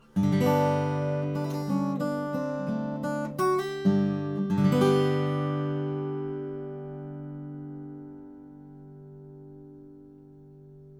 Since I have a small variety of mics, and four channels of recording capability, and a T-bar for mounting several mics in close proximity, I decided to attempt to compare some of these mics by recording a very brief clip of solo acoustic guitar.
In order to minimize proximity effect and reduce variations due to small differences in position, I placed the mics 18 inches from the guitar.
I play Hawaiian slack key nearly exclusively, so the guitar is tuned to Open G, and played with bare thumb and fingers.
The room is a rectangular drywall space, light carpeted floor, and two walls lined with vinyl LPs.
Shure KSM44 Omni
KSM44-Omni.wav